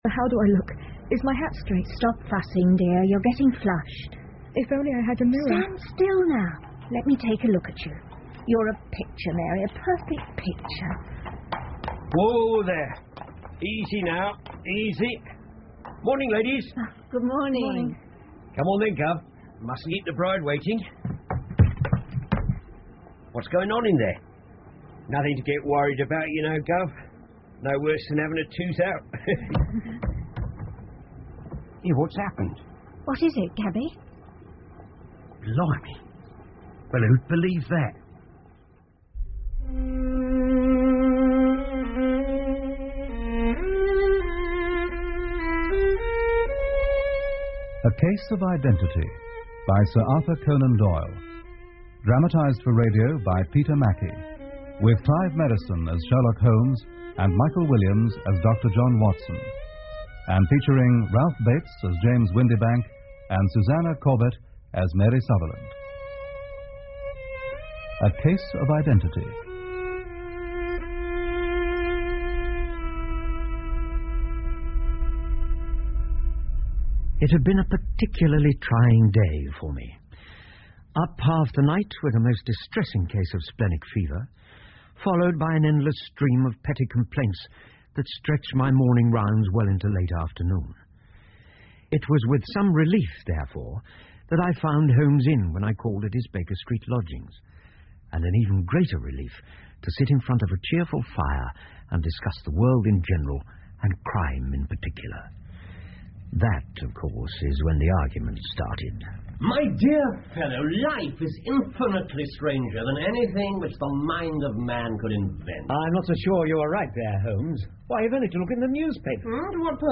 福尔摩斯广播剧 A Case Of Identity 1 听力文件下载—在线英语听力室